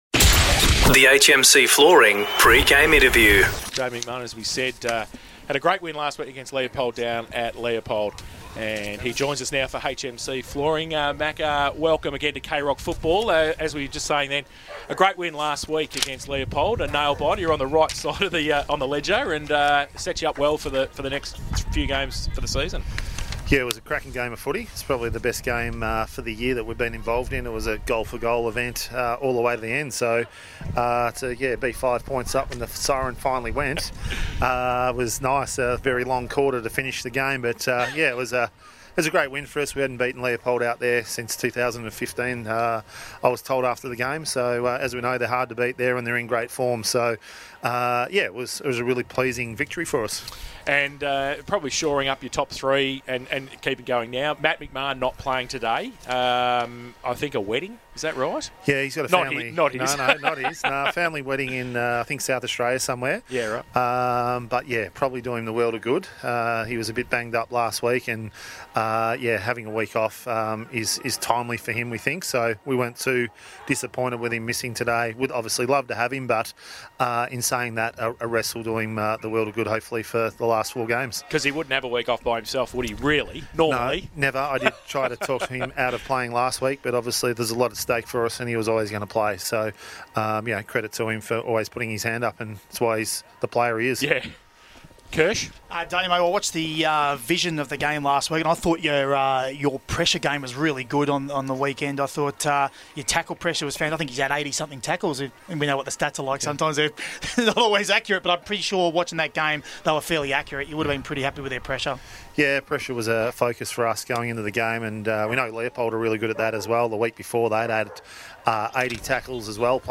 2022 – GFL ROUND 14 – NEWTOWN & CHILWELL vs. GEELONG WEST: Pre-match Interview